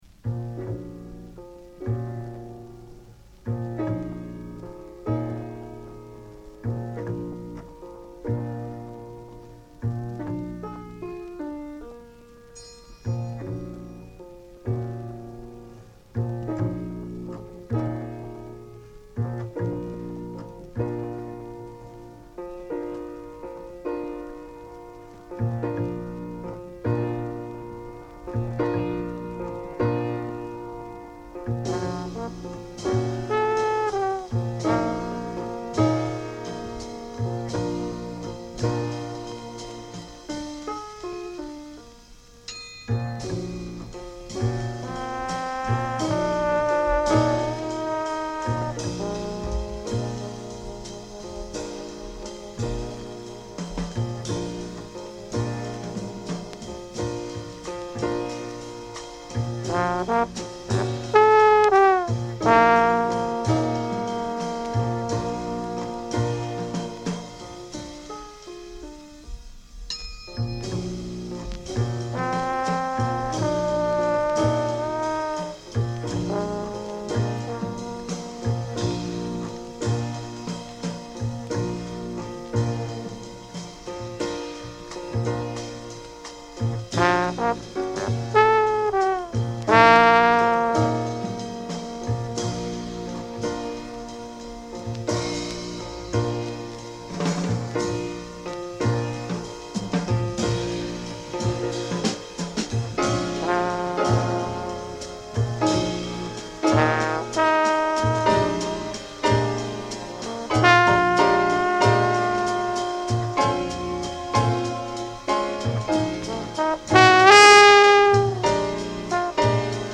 The high alter of Spiritual jazz!!